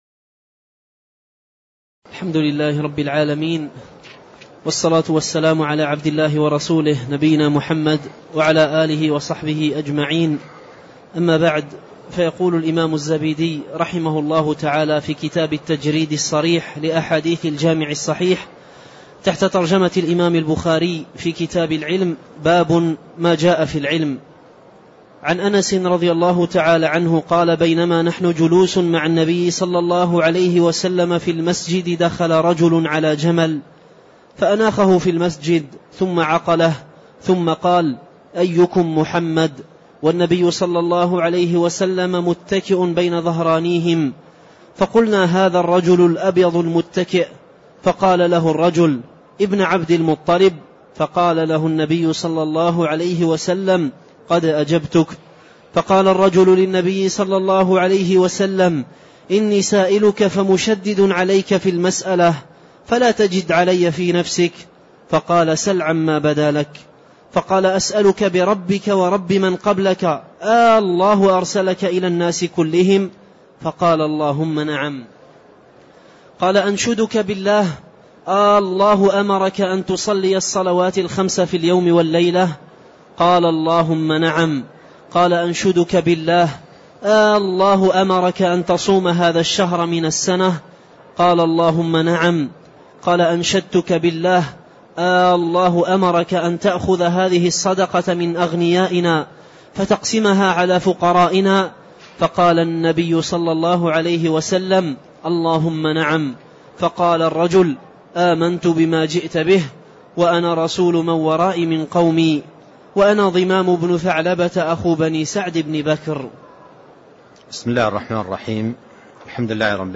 تاريخ النشر ٢٧ ربيع الثاني ١٤٣٣ هـ المكان: المسجد النبوي الشيخ